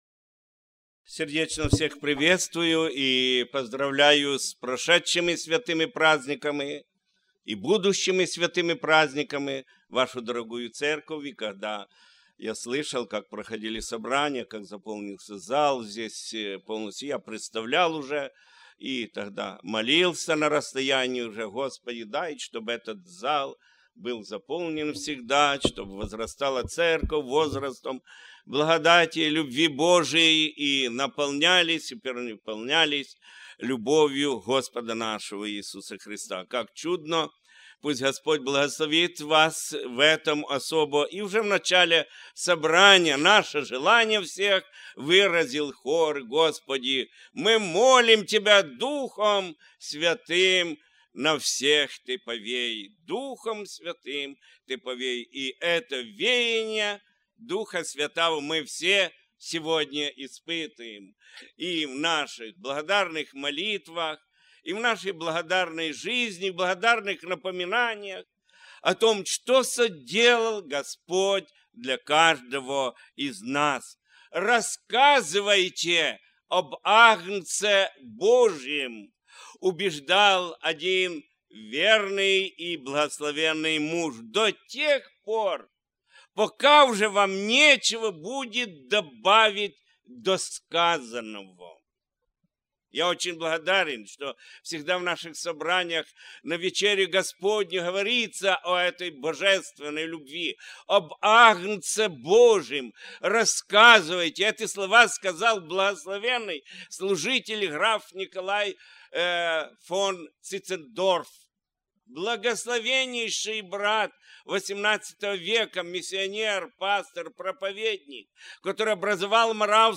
(Марка 10:33-45) Tagged with Воскресные Богослужения